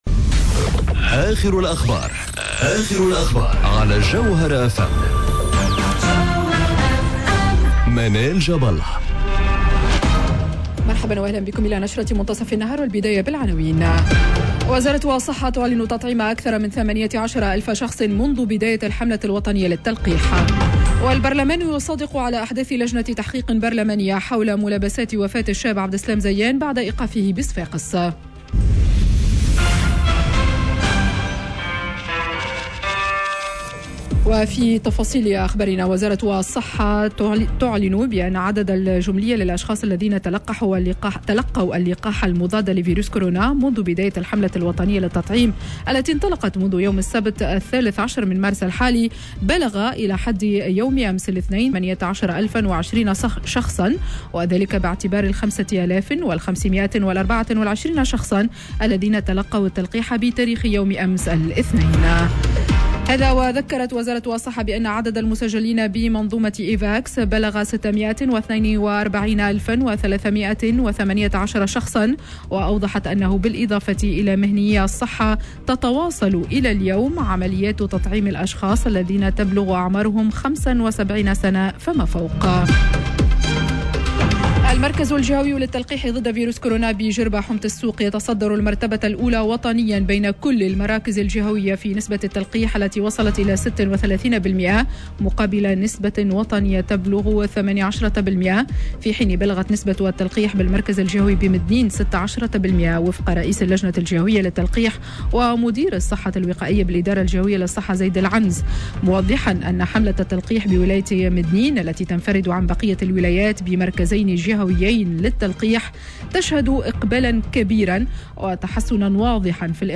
نشرة أخبار منتصف النهار ليوم الثلاثاء 23 مارس 2021